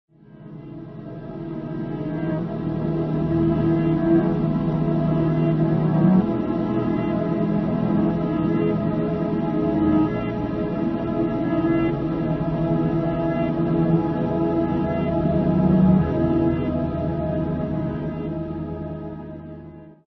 Compelling compositions, haunting and
mysterious